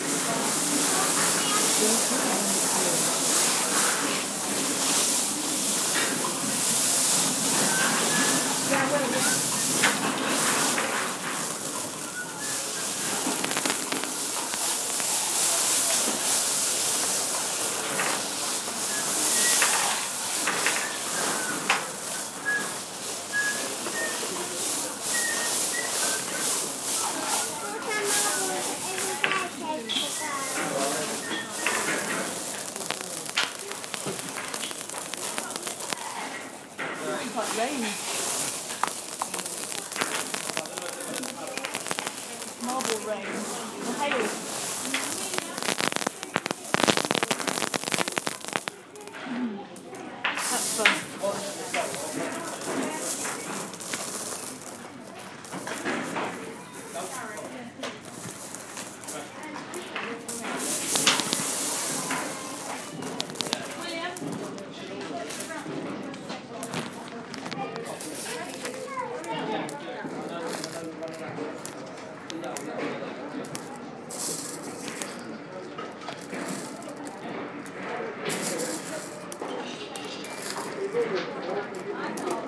It's Raining Marbles! ... Millions of them!